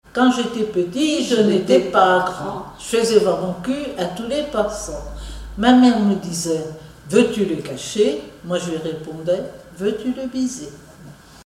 formulette enfantine : amusette
comptines et formulettes enfantines